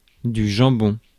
Ääntäminen
France: IPA: [dy ʒɑ̃.bɔ̃]